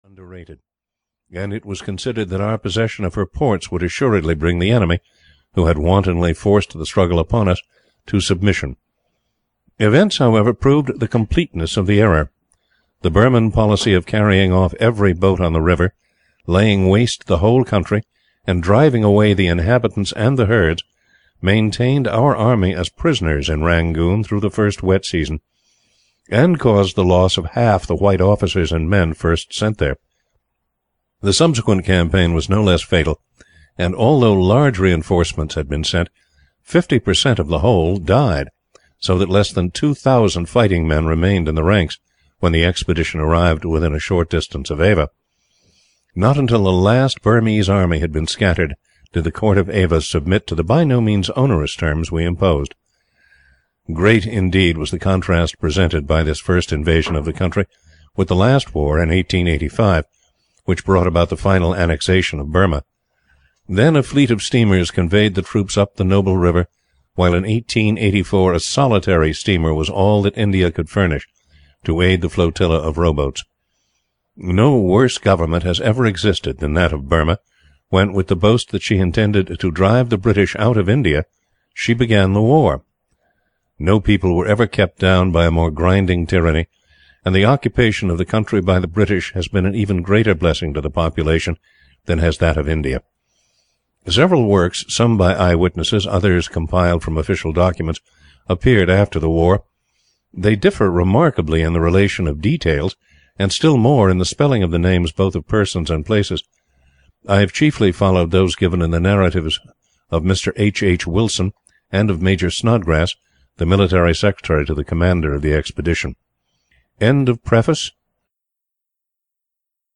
Audio knihaOn the Irrawaddy, A Story of the First Burmese War (EN)
Ukázka z knihy